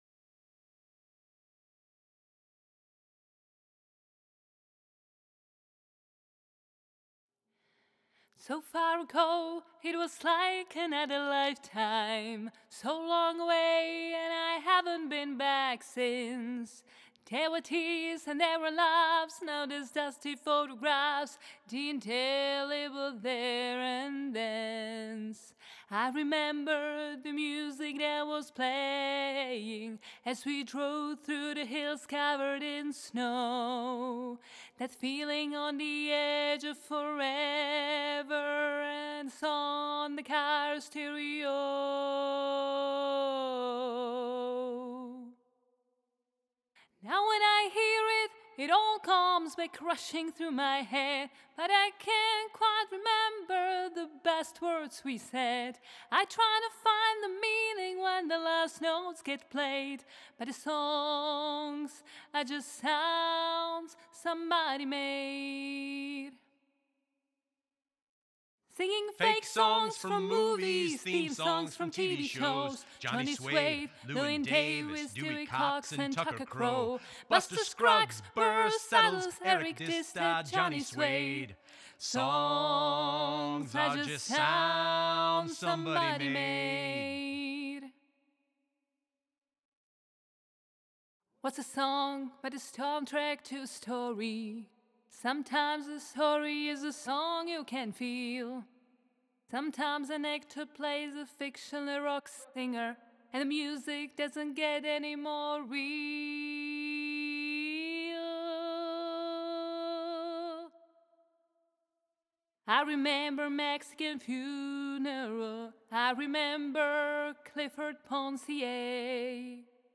bvox.wav